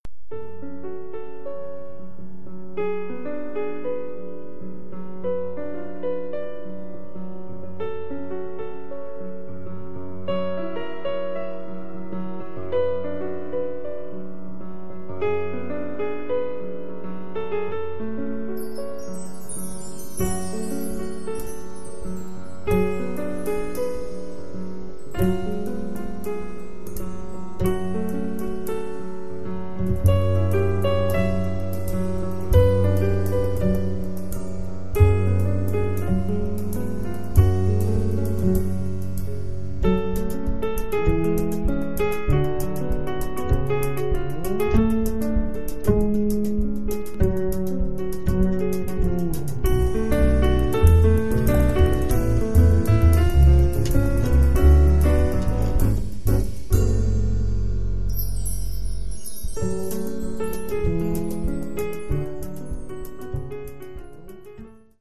bass
drums
piano